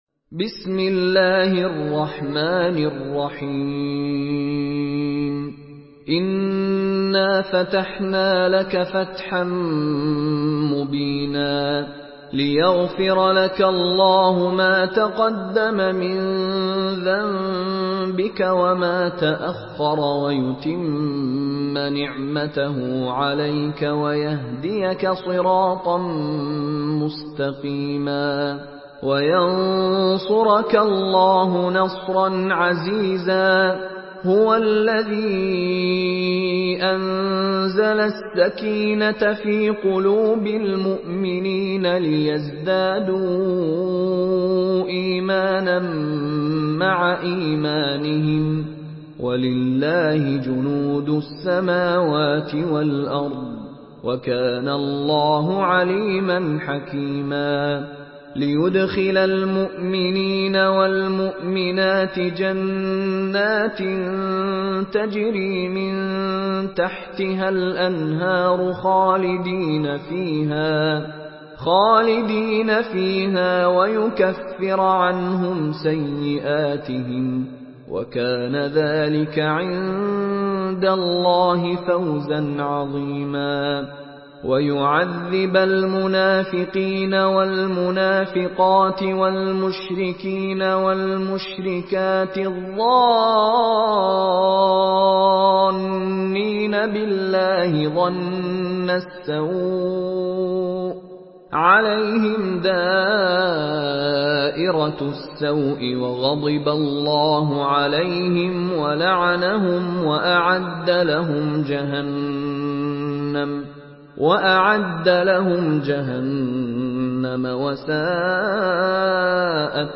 Surah Al-Fath MP3 by Mishary Rashid Alafasy in Hafs An Asim narration.
Murattal Hafs An Asim